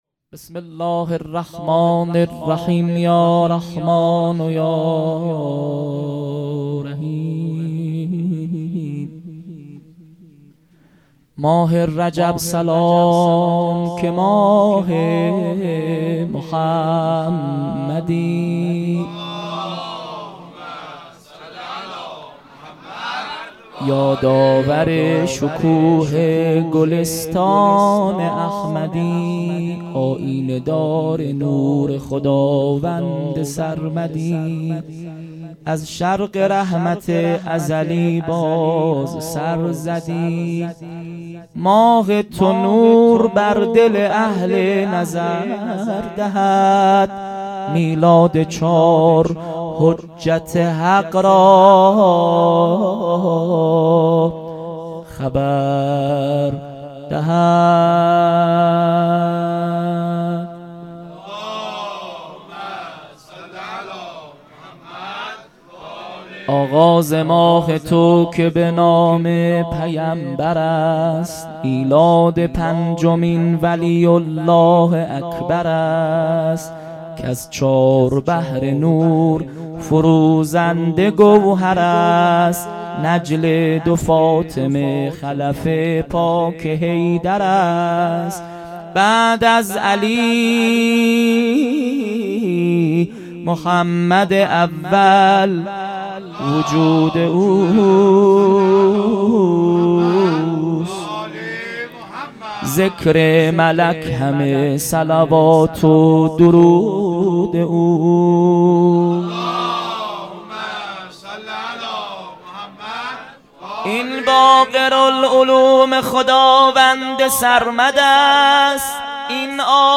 هیئت مکتب الزهرا(س)دارالعباده یزد - مدح | ماه رجب سلام که ماه محمدی مداح